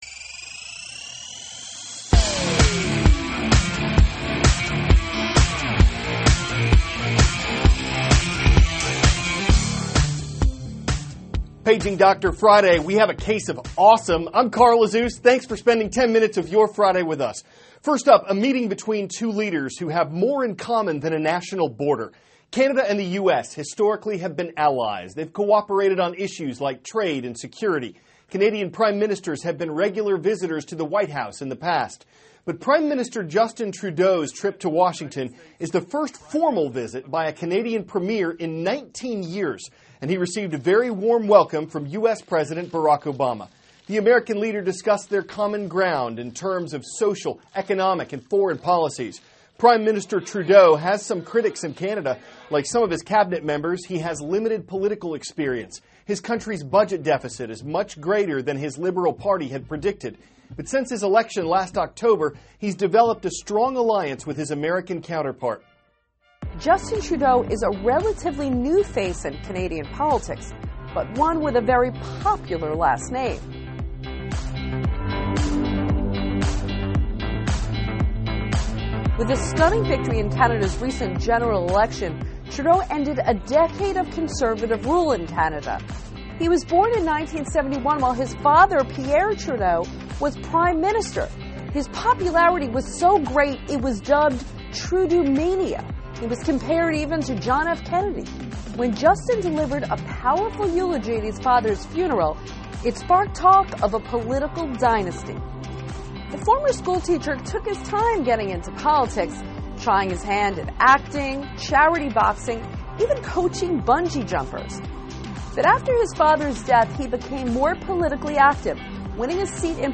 (BEGIN VIDEOTAPE) ELISE LABOTT, CNN GLOBAL AFFAIRS CORRESPONDENT: Justin Trudeau is a relatively new face in Canadian politics, but one with a very popular last name.